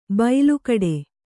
♪ bailukaḍe